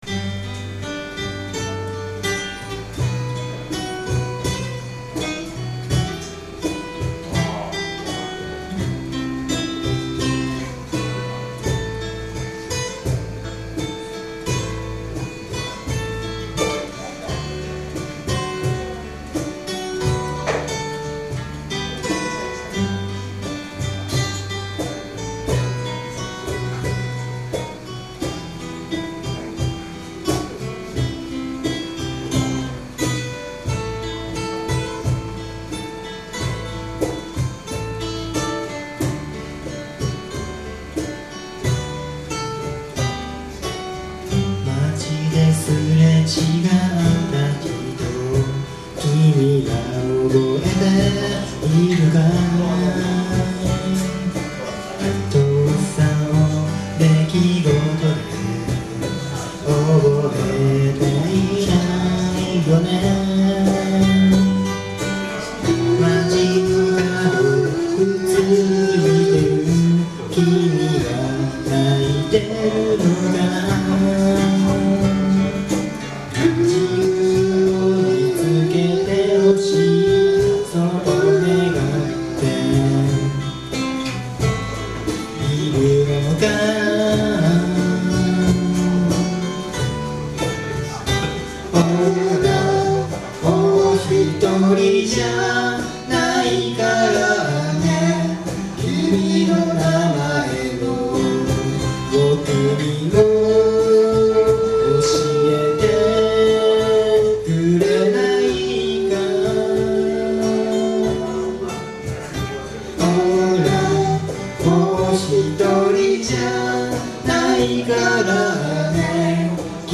Bluegrass style Folk group
Key of G
企画名: Green Grass活動七周年記念ライブ
録音場所: 風に吹かれて(大森)
ボーカル、ギター
コーラス、ベース
カホン